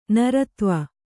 ♪ naratva